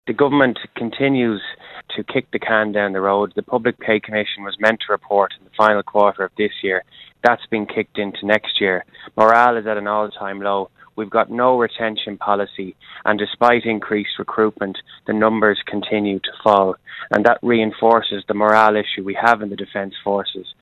Deputy Chambers believes the Government isn’t doing enough: